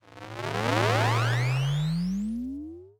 reload_ex.ogg